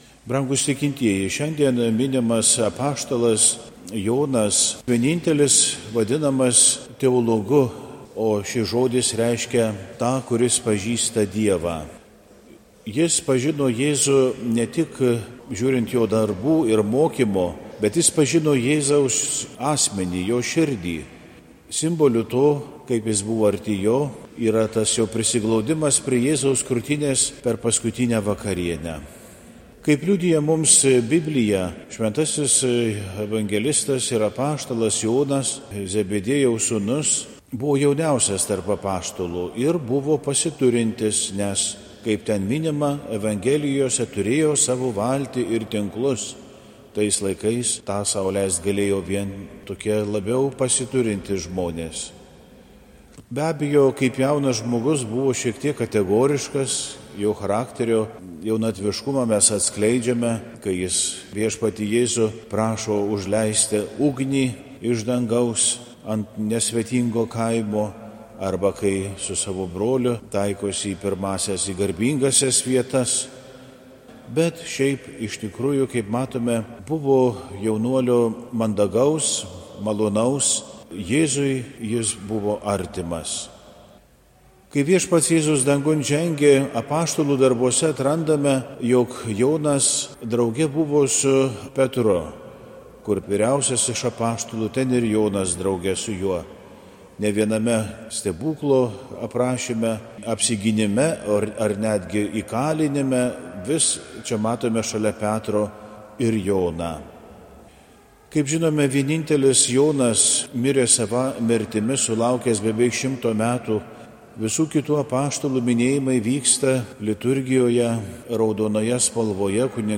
Pamokslai